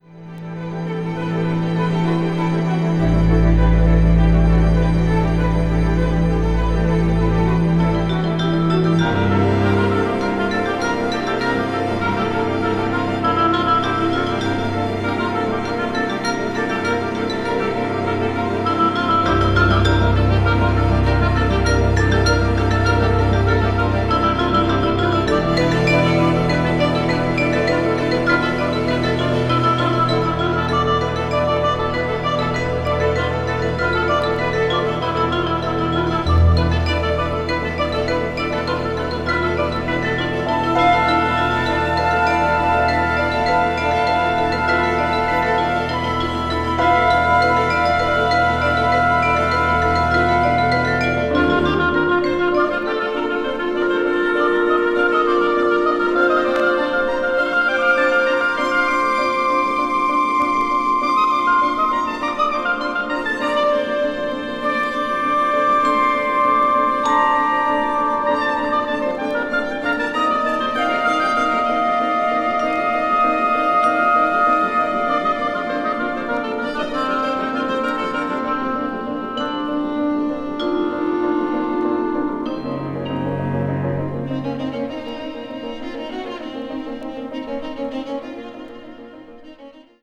ウォルト・ディズニー・コンサートホールでのライブ・レコーディング音源を収録
avant-garde   contemporary   minimal   orchestra